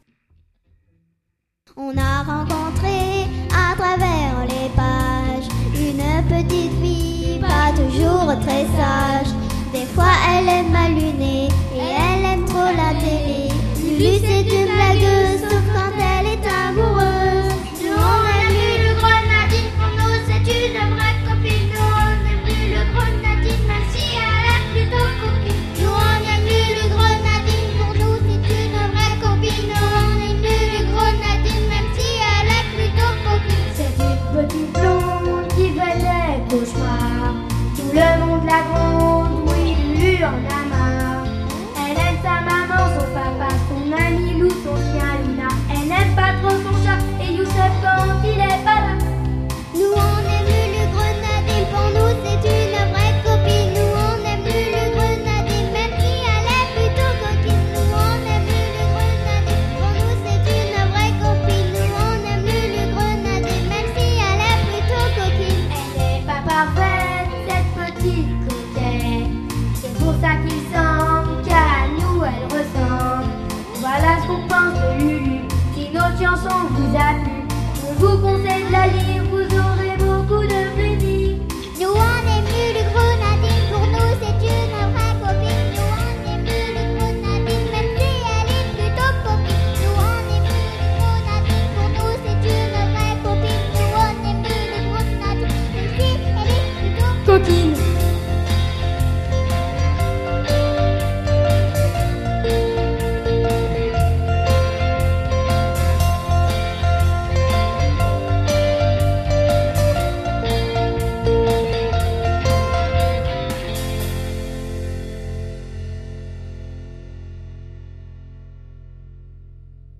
C'est une classe qui l'a inventée avec son professeur et qui la chante